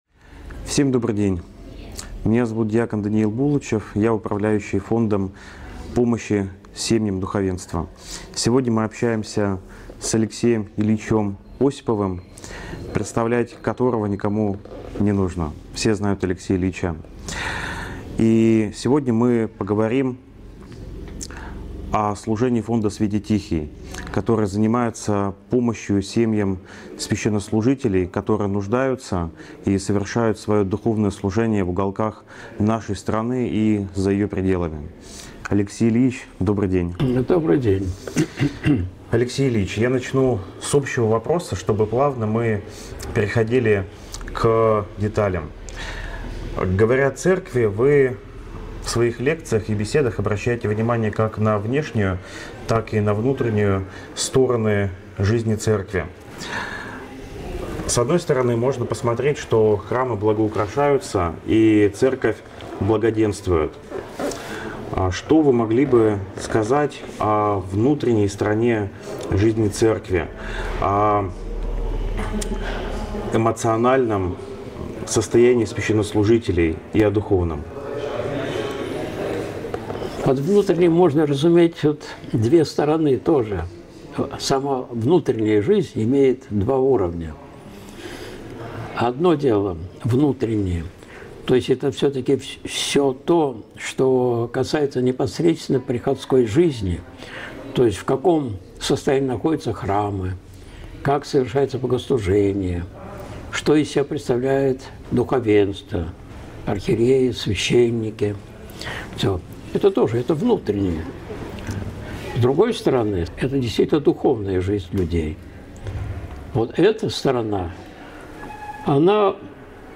Интервью фонду помощи священникам (15.11.2024)